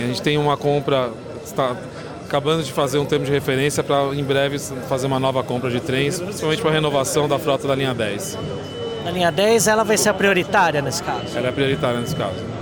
A informação é do presidente da empresa, Pedro Moro, durante entrega da unidade de número 61 na manhã desta terça-feira, 09 de abril de 2019.
Sem falar em número de composições, o presidente da CPTM, Pedro Moro, disse que os termos de referência estão sendo elaborados para a próxima compra.